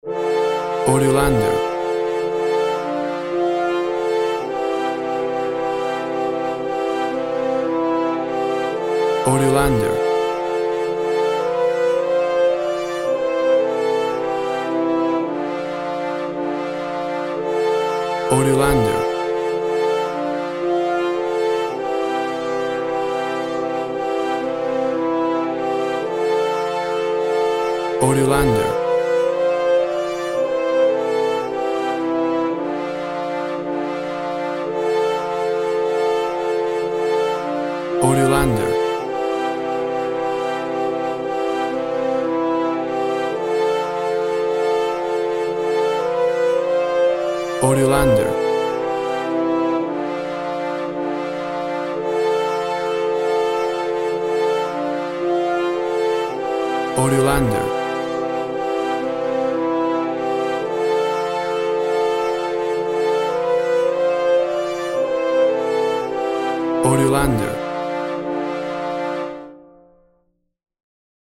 A beautiful Brass arrangement
traditional tune
WAV Sample Rate 16-Bit Stereo, 44.1 kHz
Tempo (BPM) 115